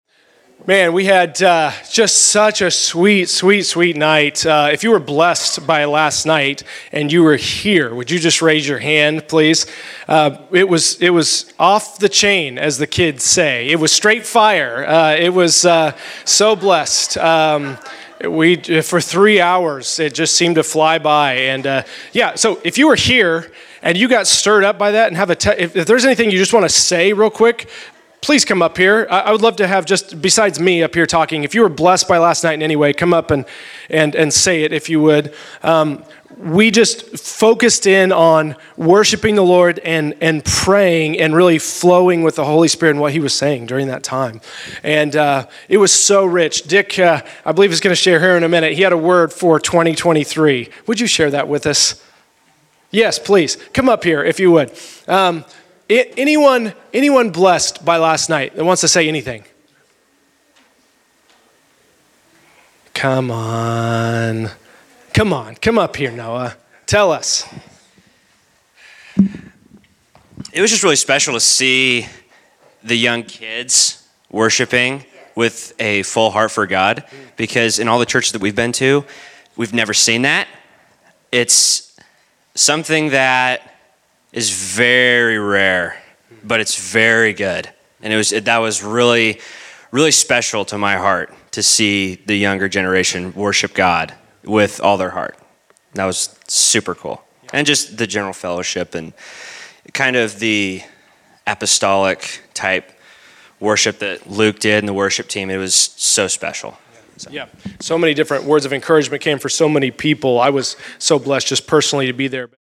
Prayer & Worship Night Testimony